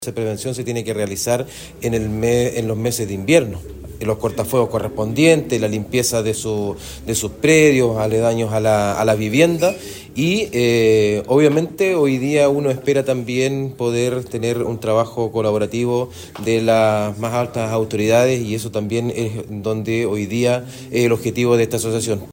Por eso, Rodrigo Montero, alcalde de Florida, añadió que el trabajo colaborativo entre comunas vecinas es esencial. Desde 2017 se han consumido aproximadamente 35 mil hectáreas en incendios; en 2025 fueron alrededor de 15 mil, lo que muestra avances en el trabajo colaborativo, pero sigue siendo insuficiente.